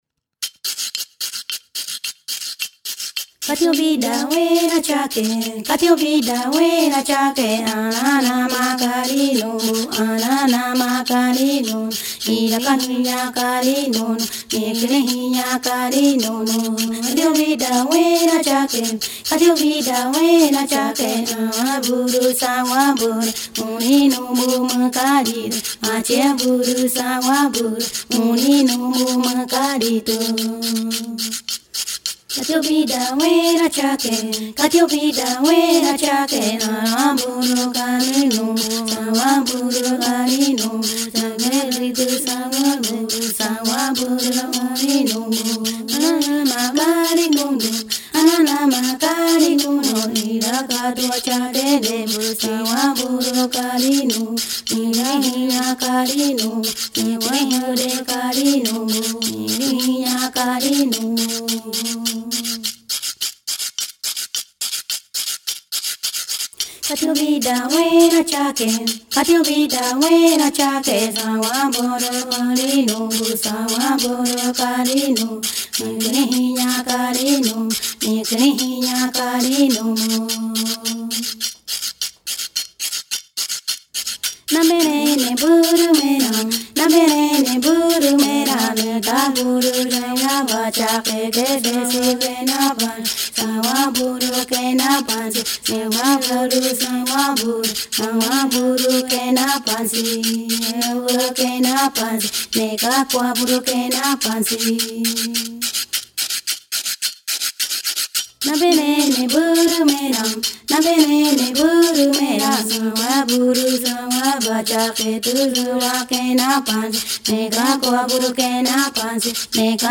voz.